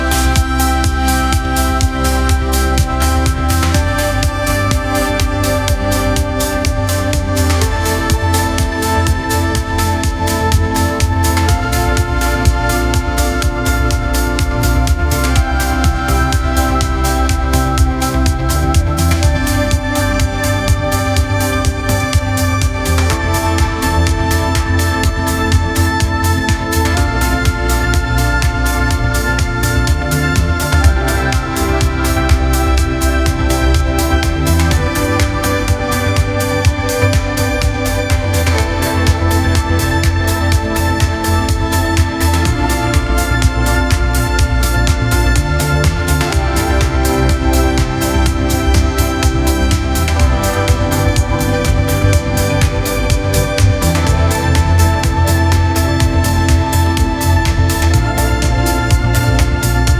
"bpm": 124,
"time_sig": "4/4",
"text_chords": "C C G G A:min A:min F F",